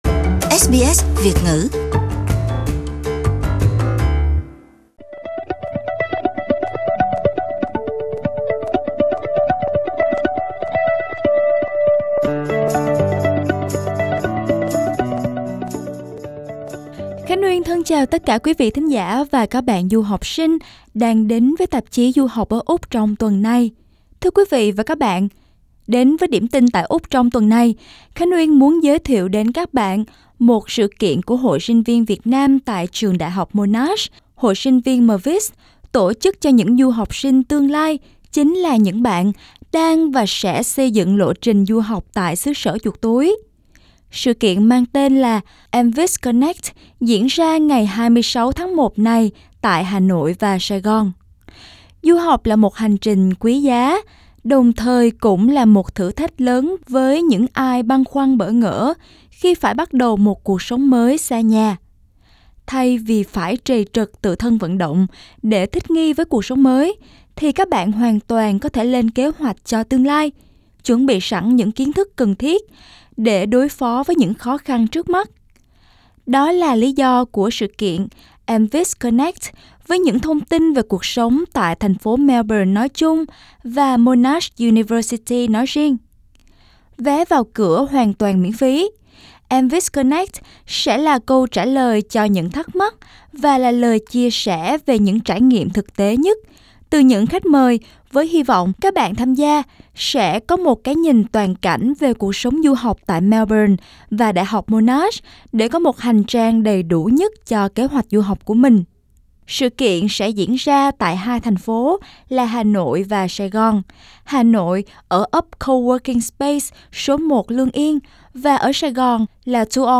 Buổi phỏng vấn với các du học sinh khách mời xoay quanh các vấn đề: -Bạn nghĩ vốn tiếng Anh lưu loát sẽ giúp ích được gì cho du học sinh ngoài chuyện học ở trường?